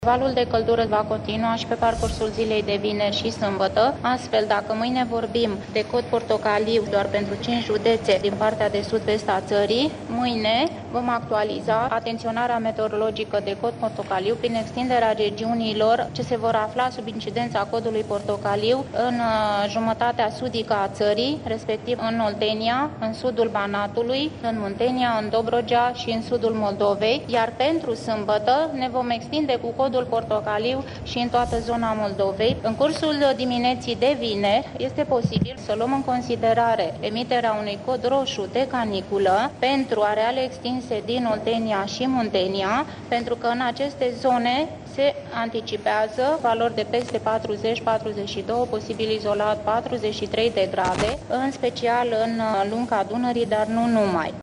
Mâine, valul de căldură se va extinde în aproape toată ţara, iar în 5 județe din sud-vestul țării va fi cod portocaliu. Directorul Administraţiei Naţionale de Meteorologie, Elena Mateescu: